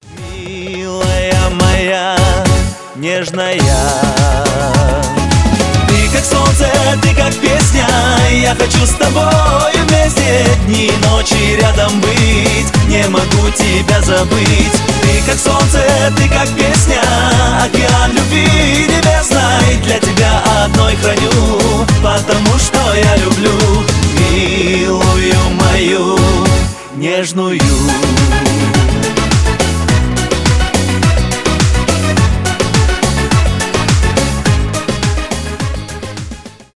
шансон
кавказские